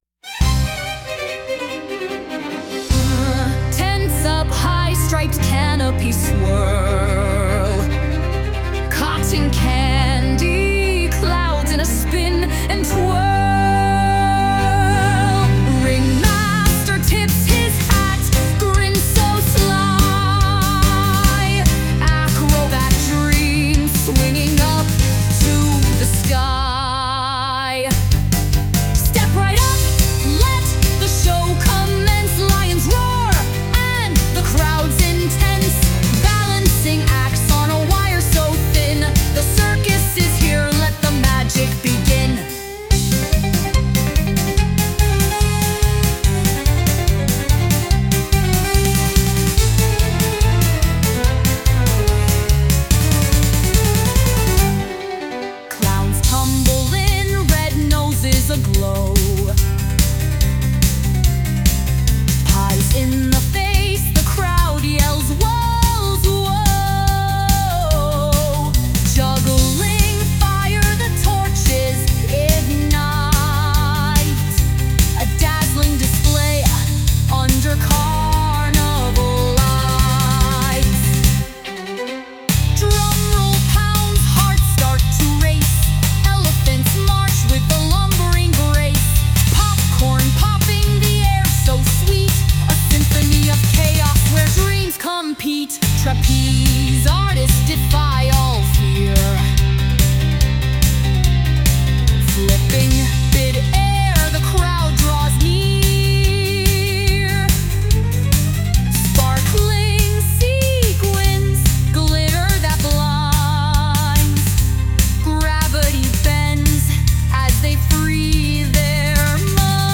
🎪 華やかなサーカスの大テントを舞台にした、にぎやかで楽しいショータイム！
ブラス、リズム、メロディが織りなす陽気なサウンドが、観客を夢と驚きの世界へ誘います。